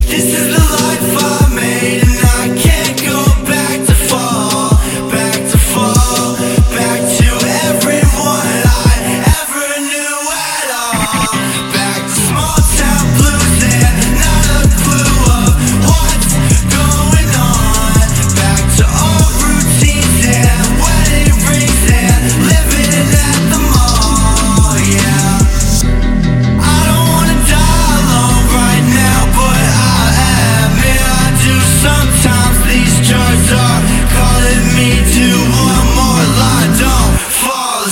• Качество: 320, Stereo
гитара
Хип-хоп
грустные
Trap
Bass